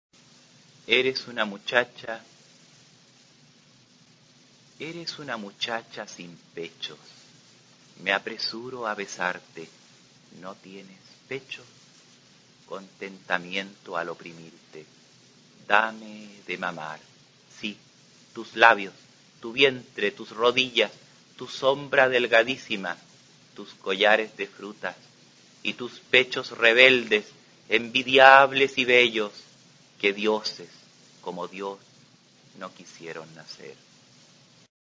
Aquí se puede oír al escritor chileno David Rosenmann-Taub leyendo su poema Eres una muchacha.